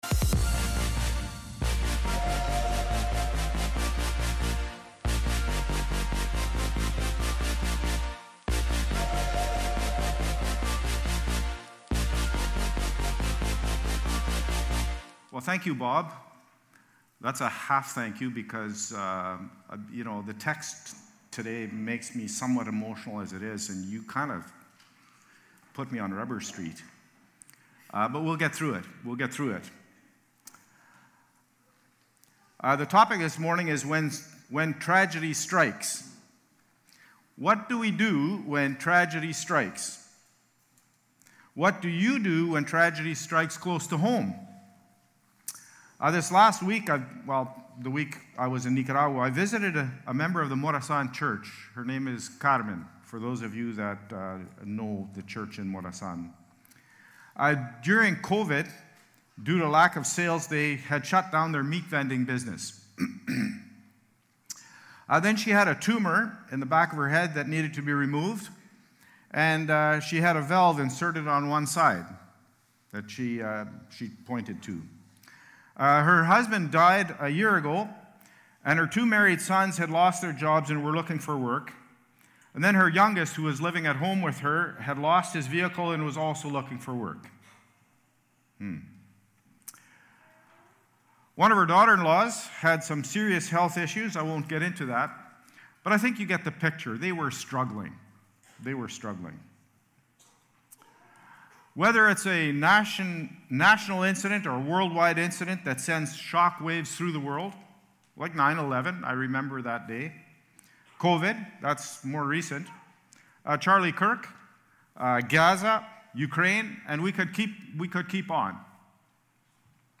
Nov-9-Worship-Service.mp3